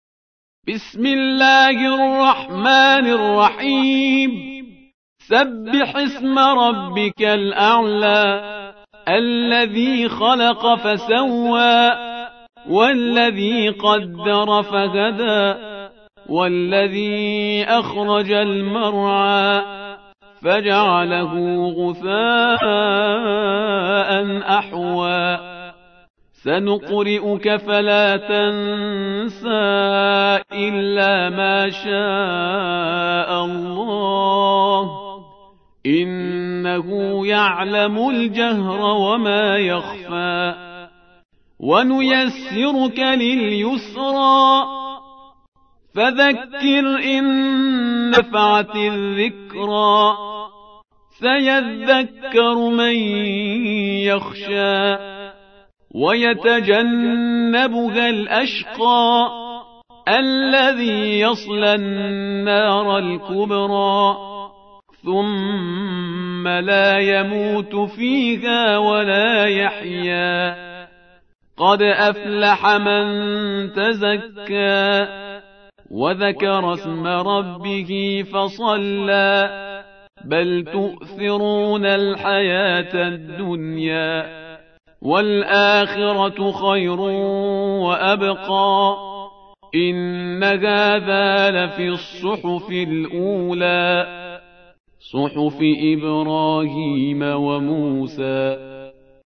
87. سورة الأعلى / القارئ